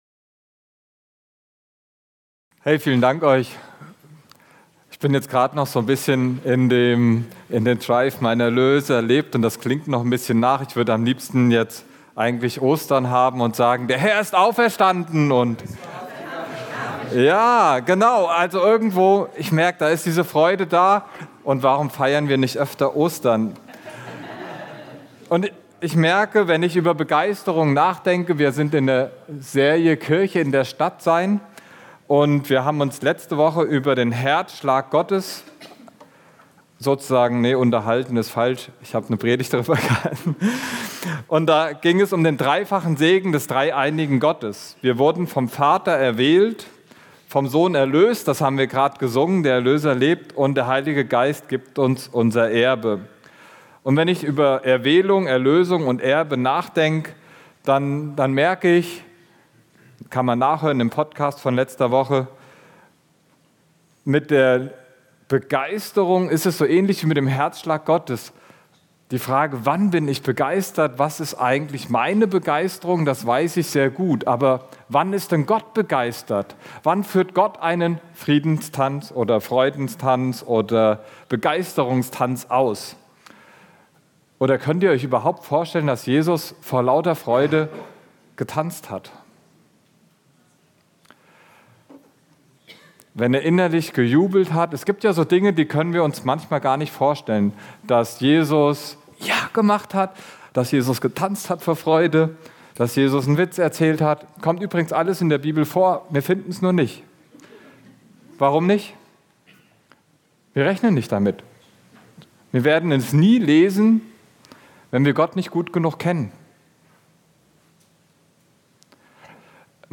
Wir sind von Gottes Liebe begeistert, machen Jesus sichtbar und investieren in Menschen 41 Minuten 36.97 MB Podcast Podcaster Steinekirche Podcast Predigten und Impulse für deinen Alltag.